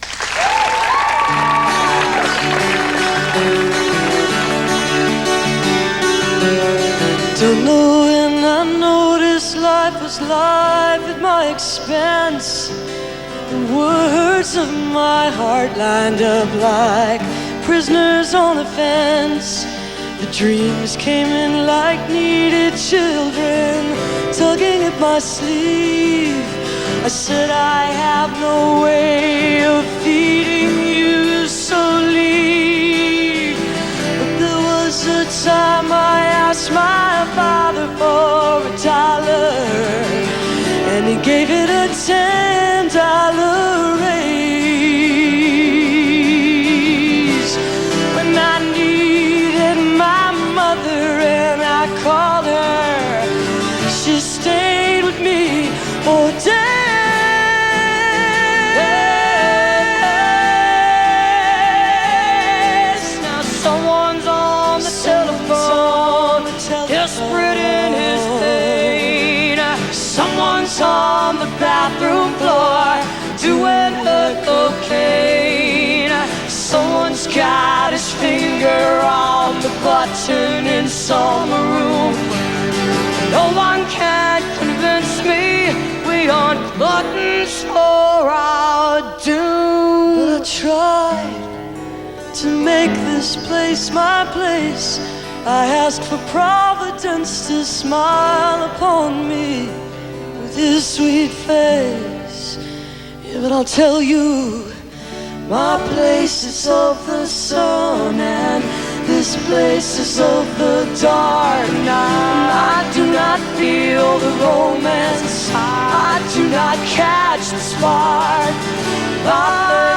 (audio capture from a video)